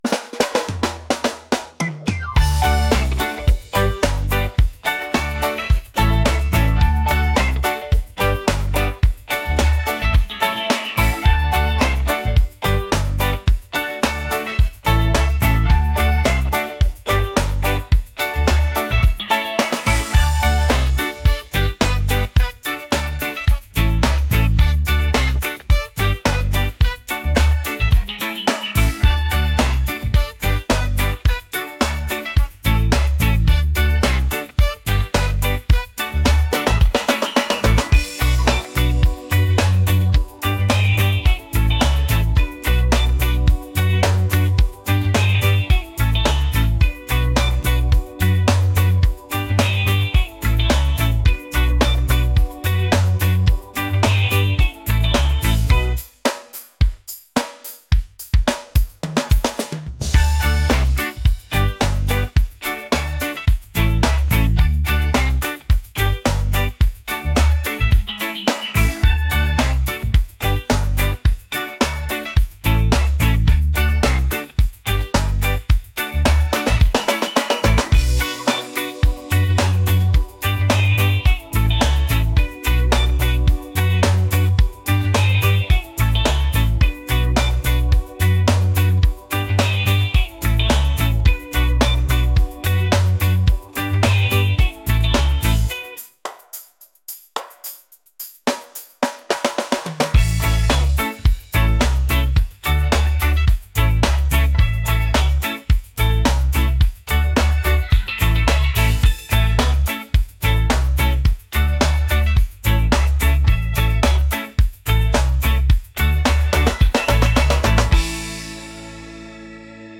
reggae | catchy | upbeat